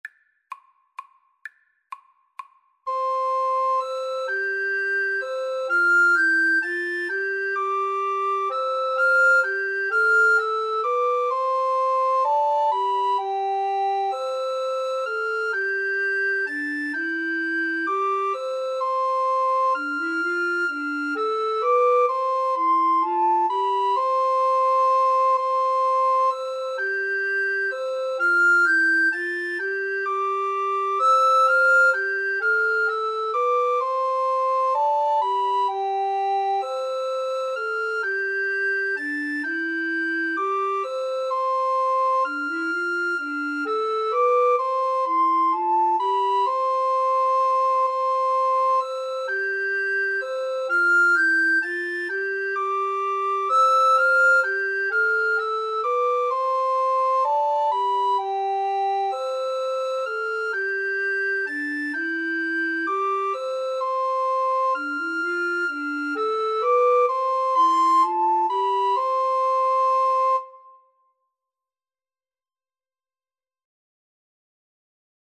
Soprano RecorderAlto RecorderTenor Recorder
C major (Sounding Pitch) (View more C major Music for Recorder Trio )
3/4 (View more 3/4 Music)
quem_pastores_SATRC_kar2.mp3